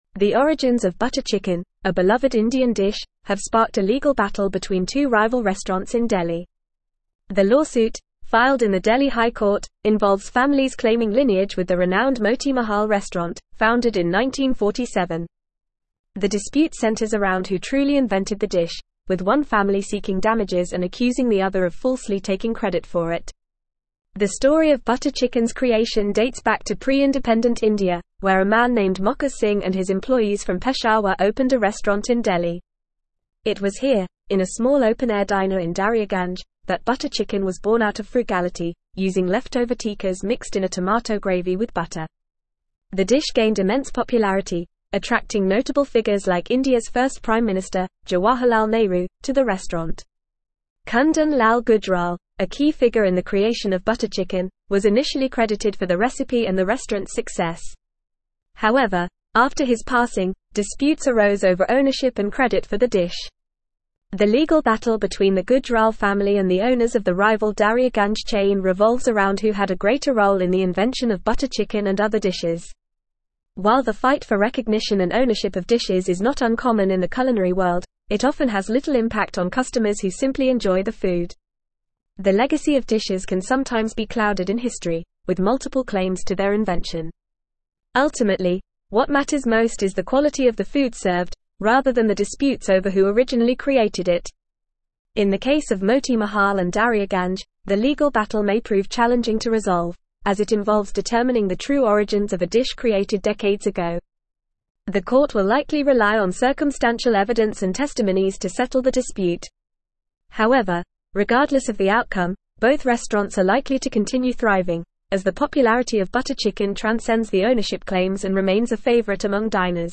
Fast
English-Newsroom-Advanced-FAST-Reading-Butter-Chicken-Origins-Delhi-Restaurants-in-Legal-Battle.mp3